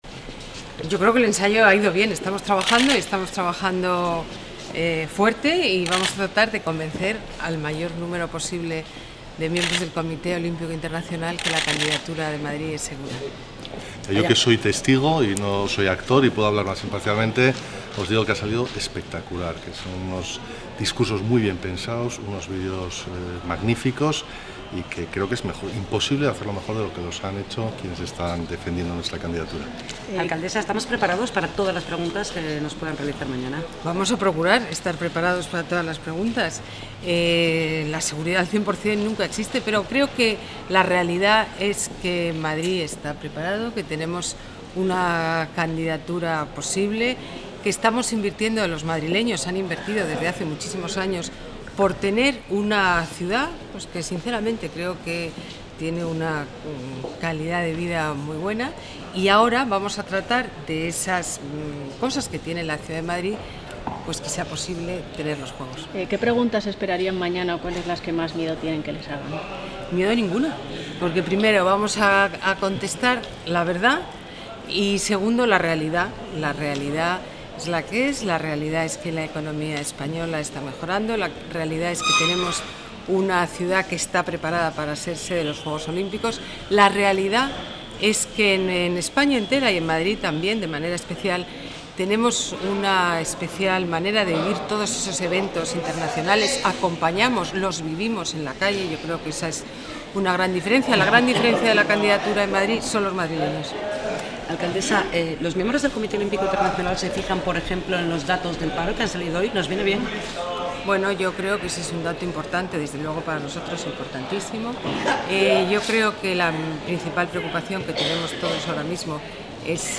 Nueva ventana:Declaraciones de la alcaldesa